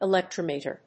音節e・lec・trom・e・ter 発音記号・読み方
/ɪlèktrάməṭɚ(米国英語), əlèktrάməṭɚ(英国英語)/